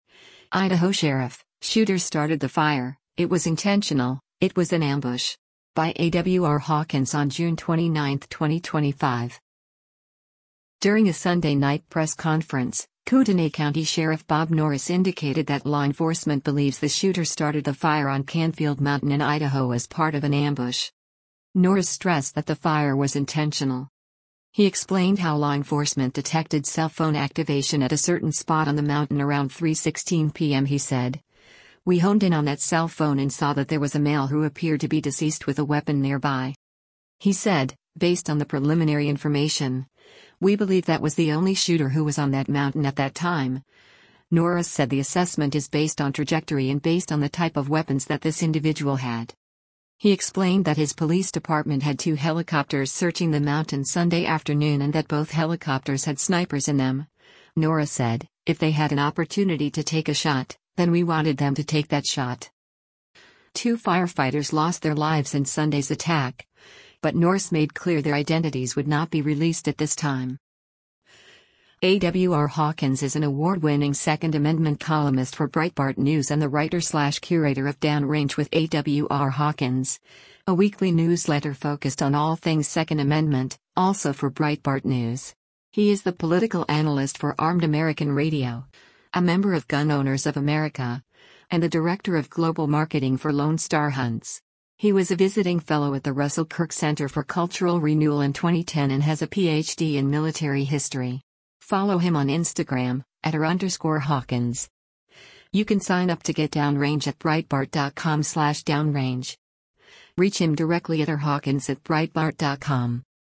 During a Sunday night press conference, Kootenai County Sheriff Bob Norris indicated that law enforcement believes the shooter started the fire on Canfield Mountain in Idaho as part of “an ambush.”